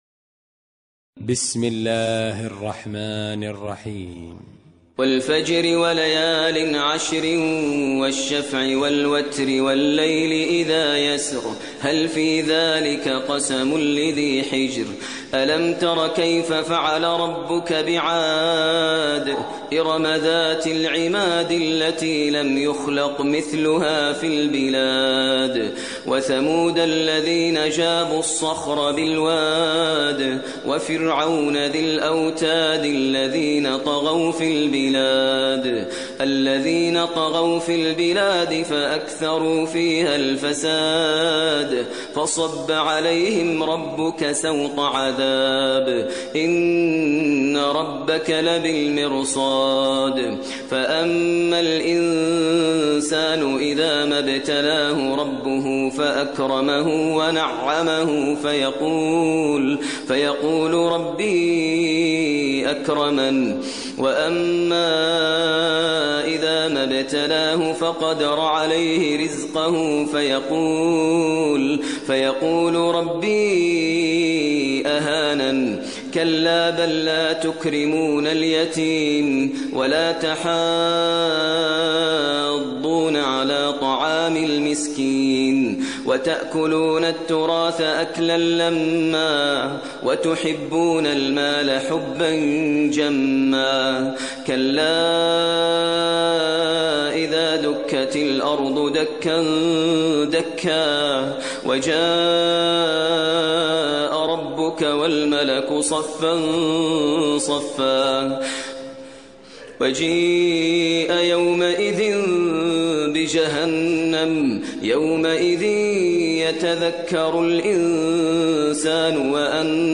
ترتیل سوره فجر با صدای ماهر المعیقلی